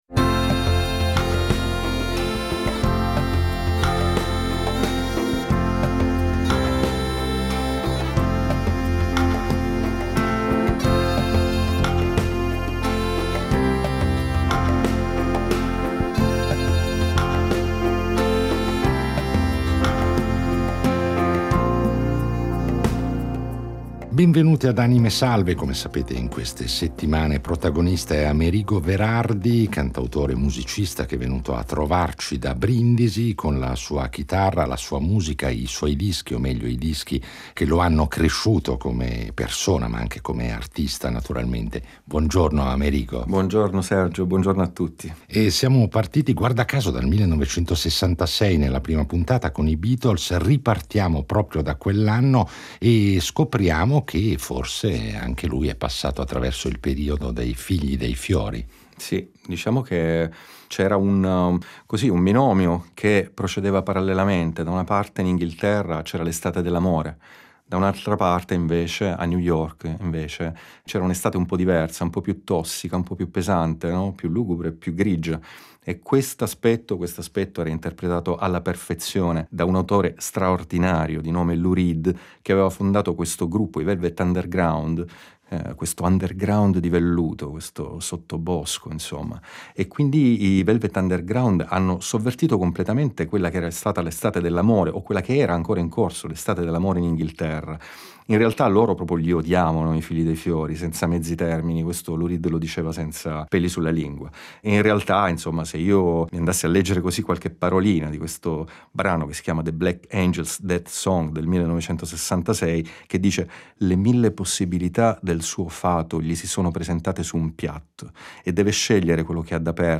Un ospite prezioso che, come sempre ad “ Anime Salve ”, è venuto a trovarci con la sua chitarra per impreziosire i nostri itinerari sonori con interventi “live”.